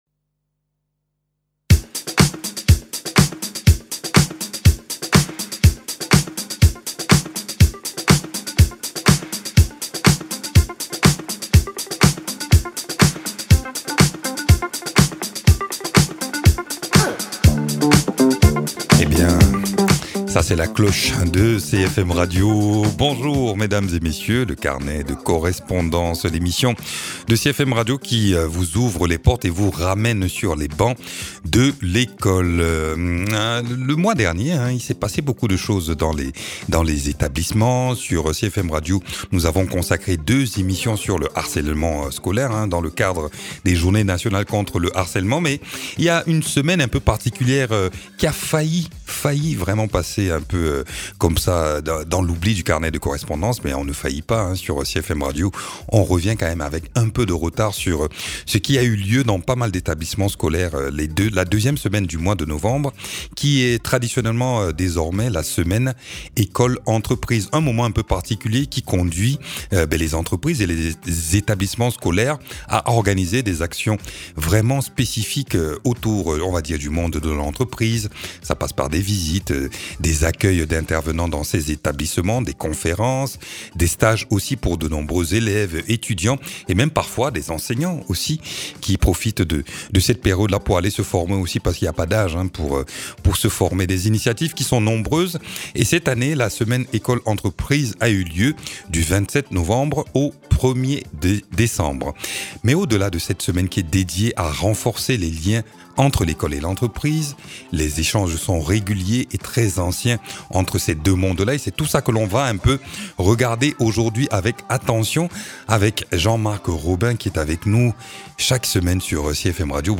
Dans ce numéro nous rappellerons les liens étroits entre les deux entités, les actions régulièrement organisées, des pistes d’évolution et surtout nous aurons le témoignage d’un chef d’entreprise à ce sujet.